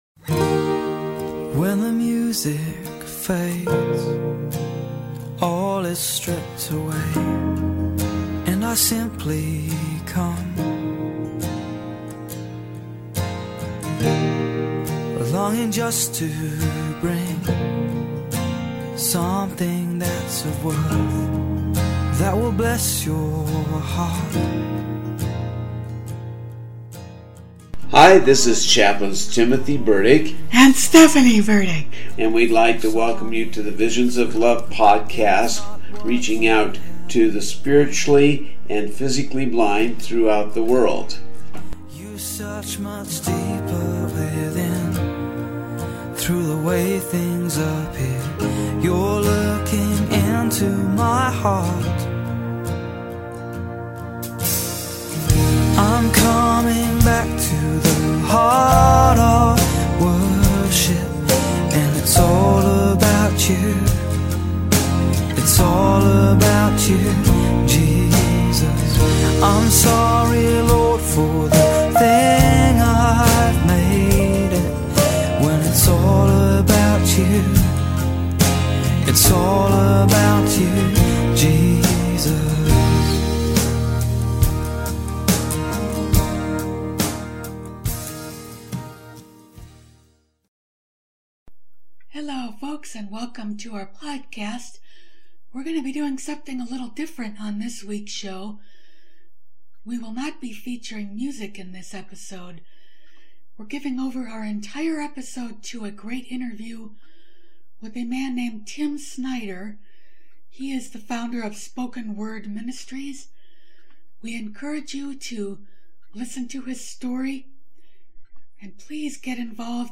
I decided to repost an interview